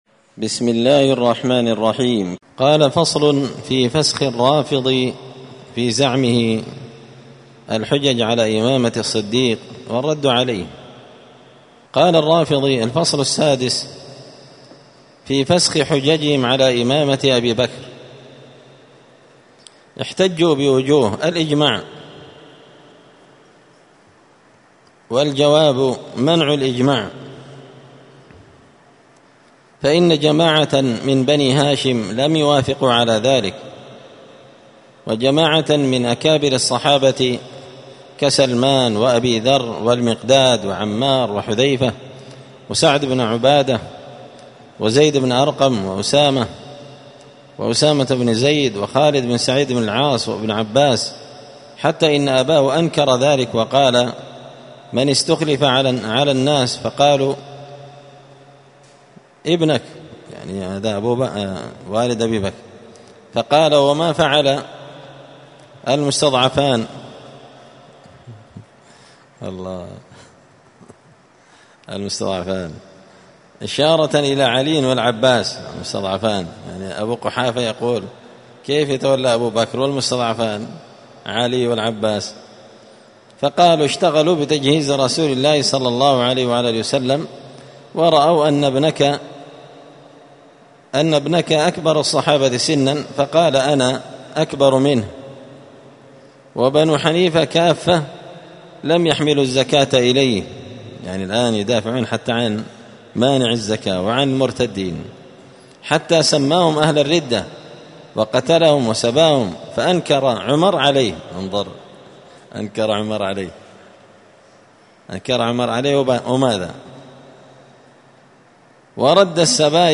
*الدرس السابع والثلاثون بعد المائتين (237) فصل في فسخ الرافضي في زعمه الحجج على إمامة الصديق والرد عليه*
مسجد الفرقان قشن_المهرة_اليمن